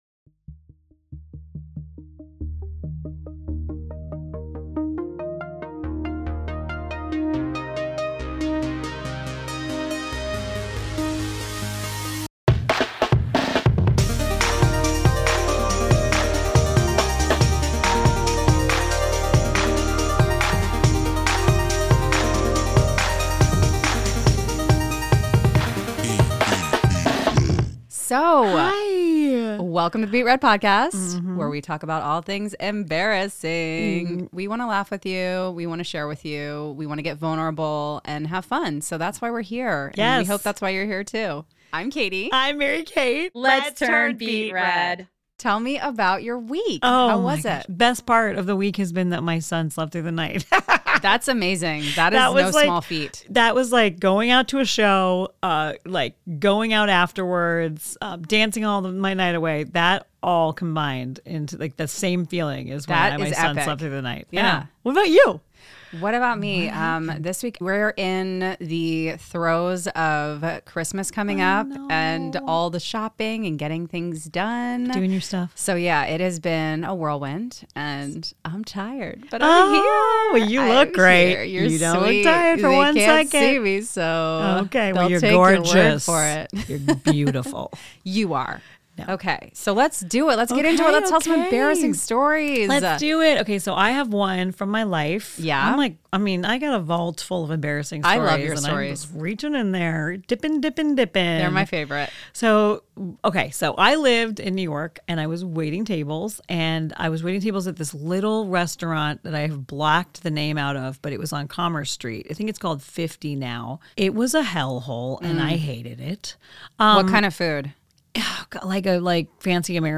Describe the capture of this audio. at PROJKT studios in Monterey Park, CA.